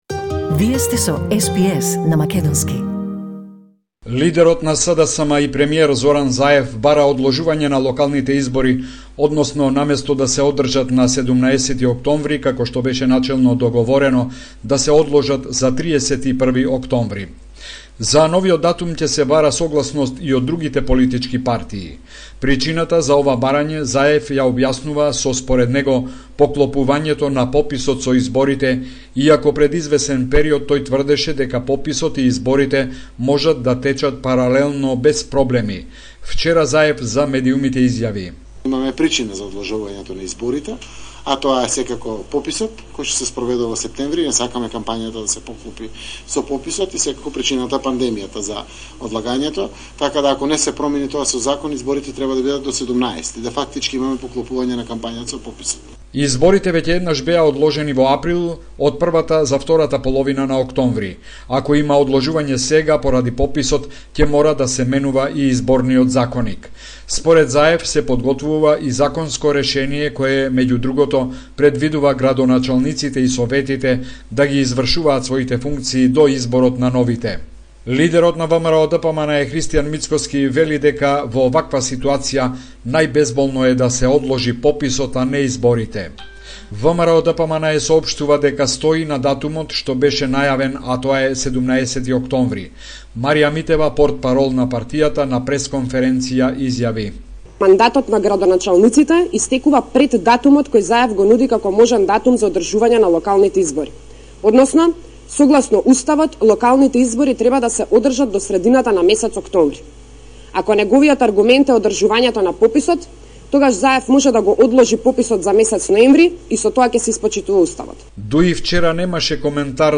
Homeland Report in Macedonian 21 July 2021